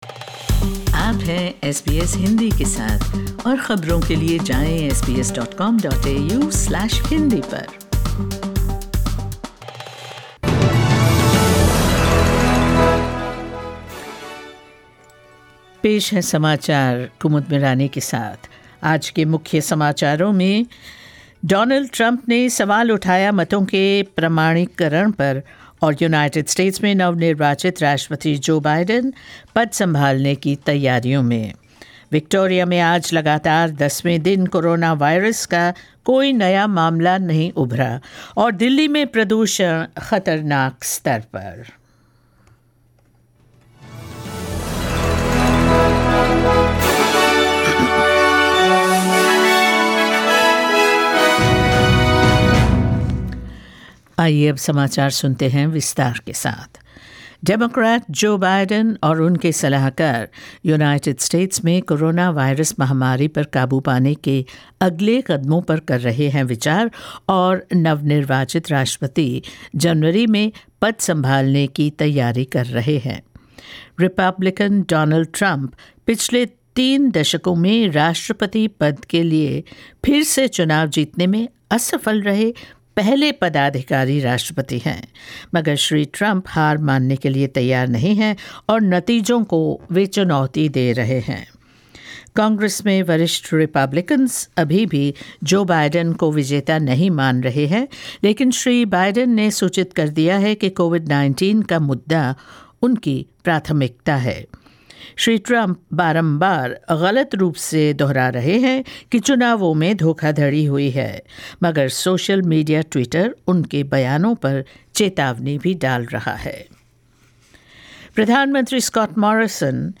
News in Hindi 09 November 2020